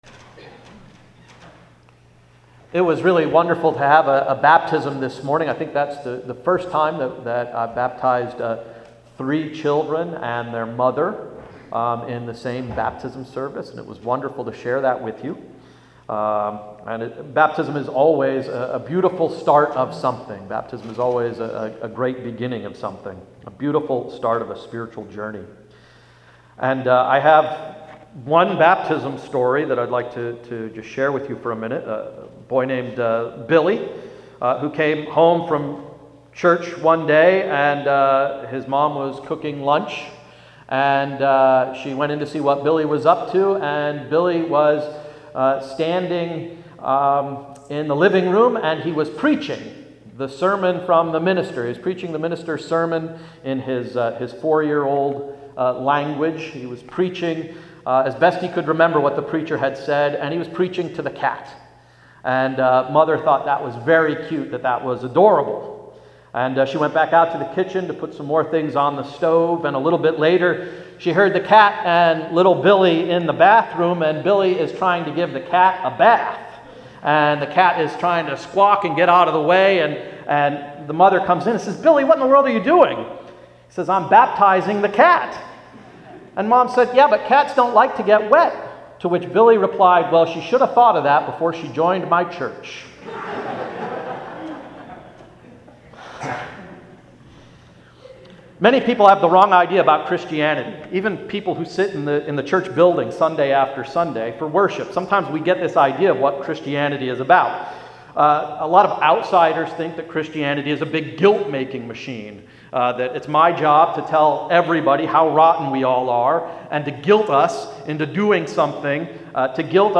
Sermon of September 25, 2011–“Sweetening Sour Grapes”